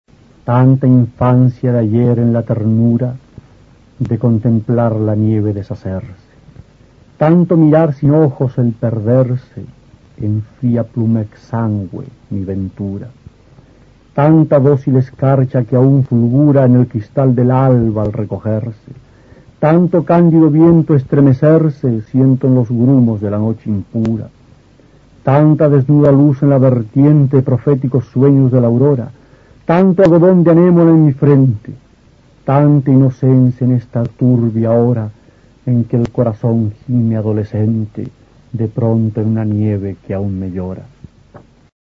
Aquí se puede escuchar al poeta chileno Roque Esteban Scarpa (1914-1995) evocando la infancia en un emotivo soneto.